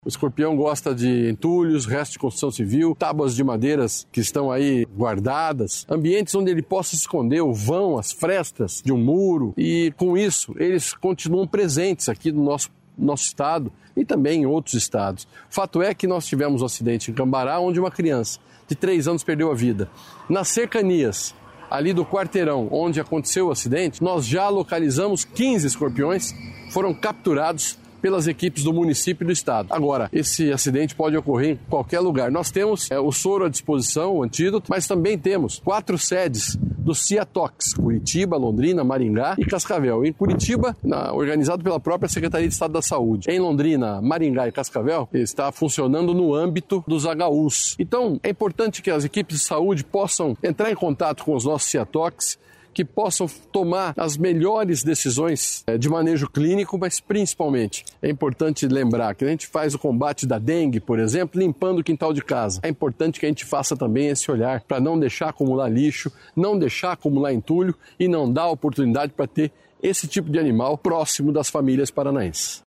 Sonora do secretário da Saúde, Beto Preto, sobre o risco de escorpiões